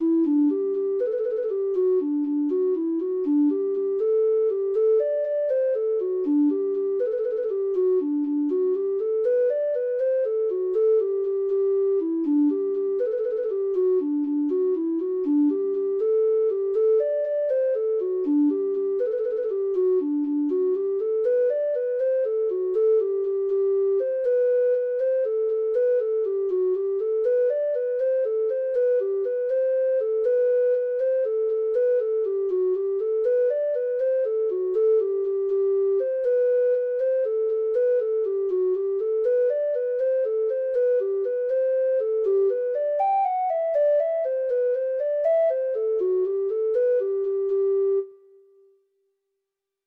Traditional Music of unknown author.